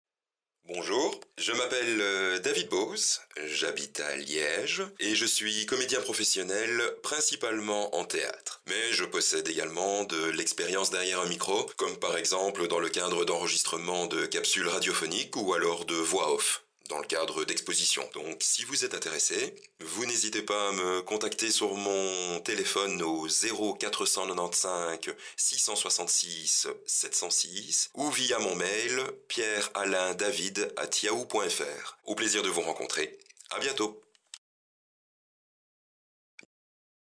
Voix off
démo vocale basique
Accent Belge